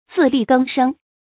注音：ㄗㄧˋ ㄌㄧˋ ㄍㄥ ㄕㄥ
自力更生的讀法